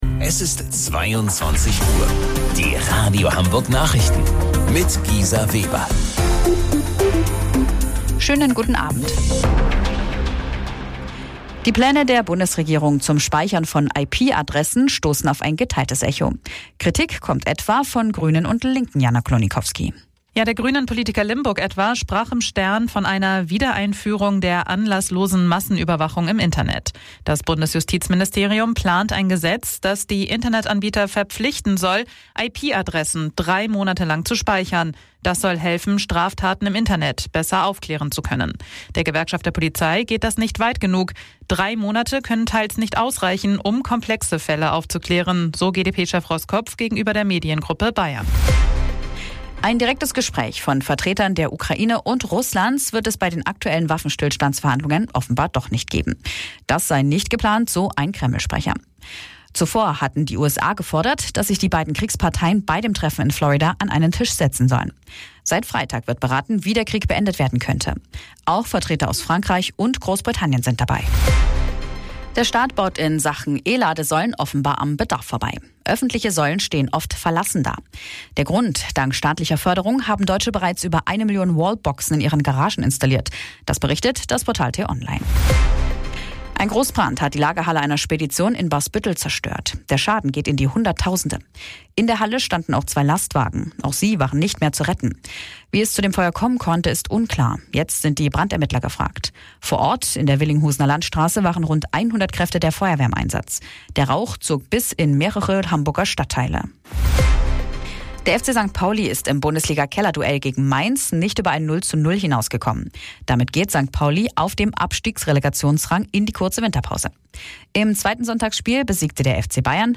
Radio Hamburg Nachrichten vom 21.12.2025 um 22 Uhr